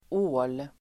Ladda ner uttalet
Uttal: [å:l]